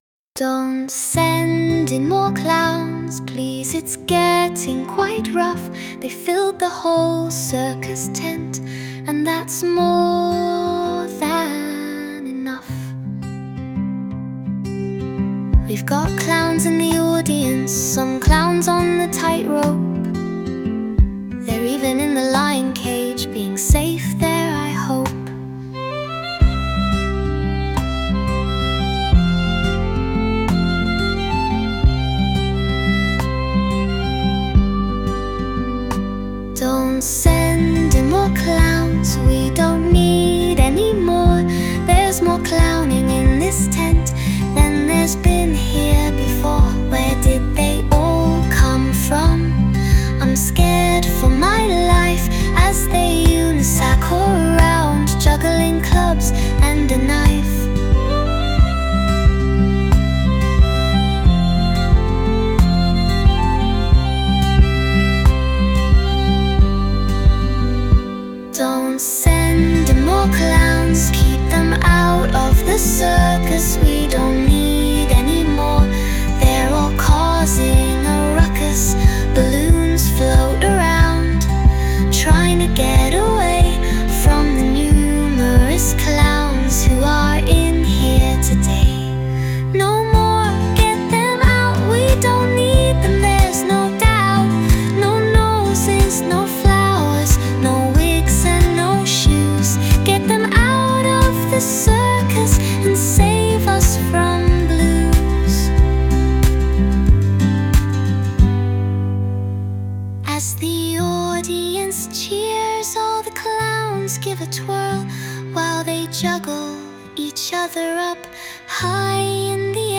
Lyrics : By me